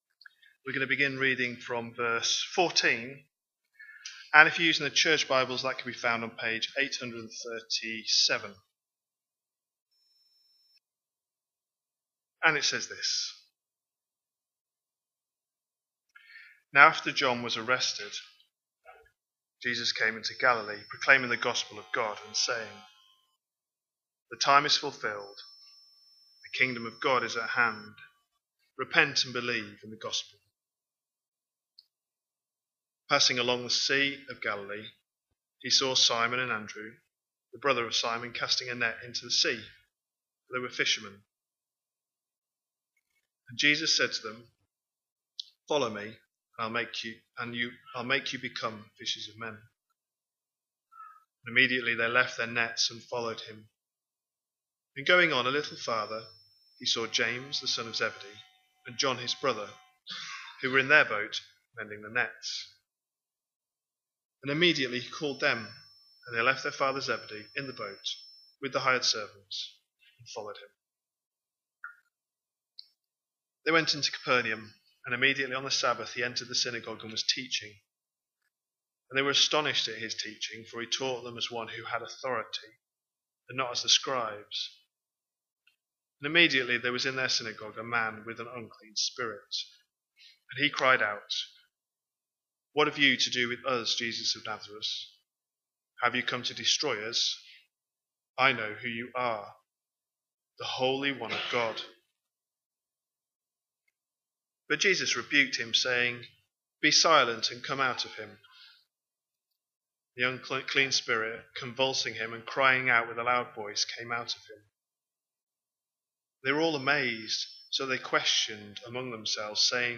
A sermon preached on 12th October, 2025, as part of our Mark 25/26 series.